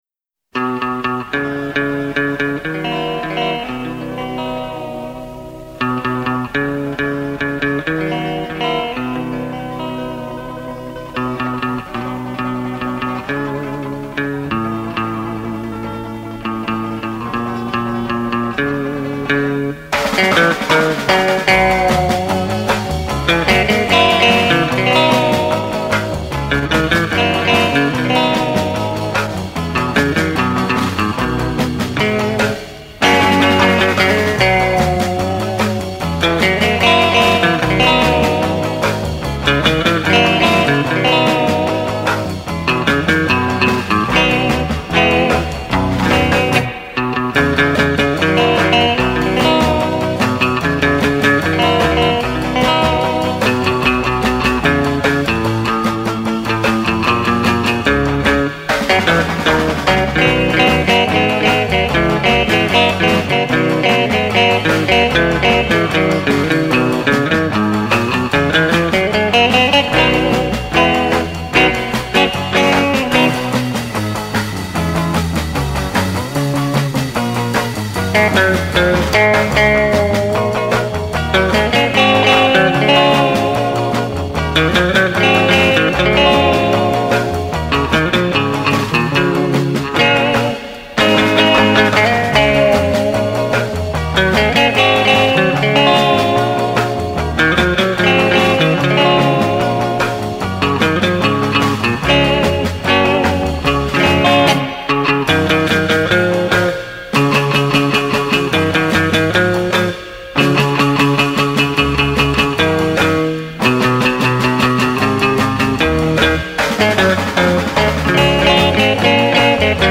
毎度言うが音は他所からひっぱったものを少し加工してる。
音質は期待しないでね。
リズムギター
ベースギター
ドラムス
循環コードの羅列になってるのか。
と思しき美しい音色の、トレモロアームを多用した演奏は楽しい。